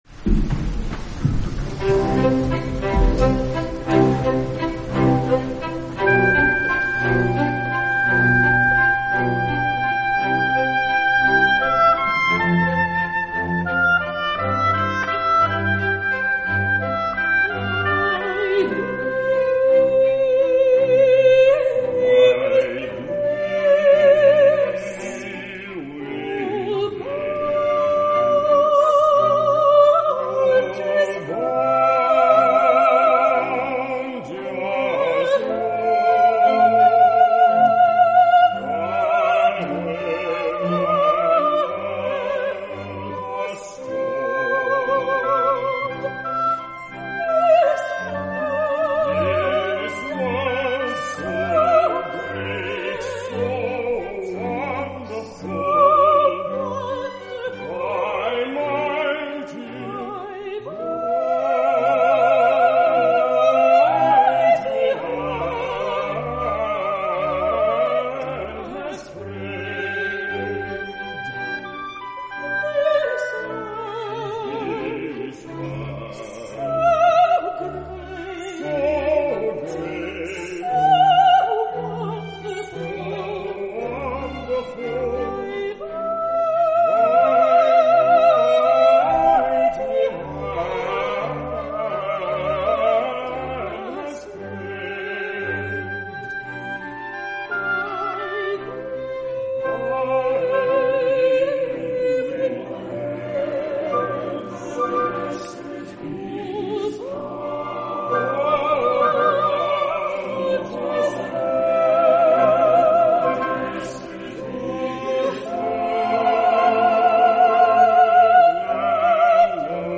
By Thee with Bliss (Adam & Eve Duet) from Franz Joseph Haydn’s Oratorio The Creation.
soprano
tenor
bass